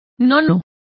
Complete with pronunciation of the translation of ninth.